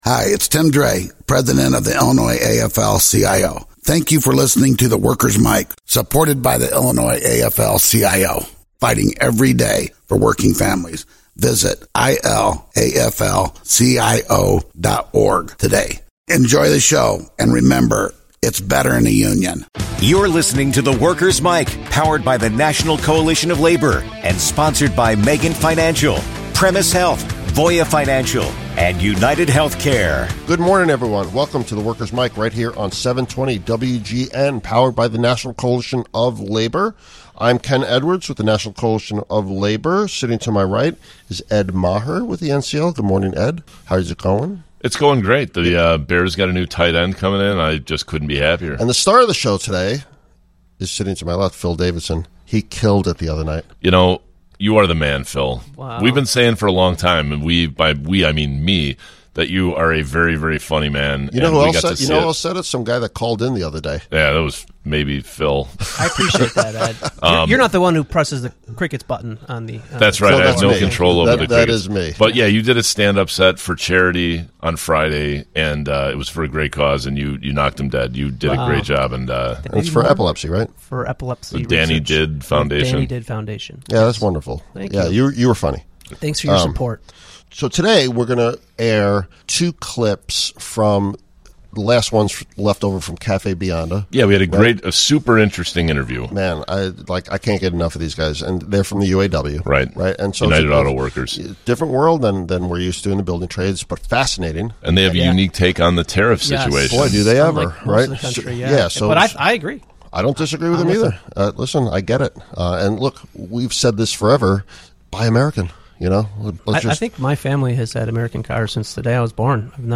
kick off the show by playing back some audio from their Café Bionda live show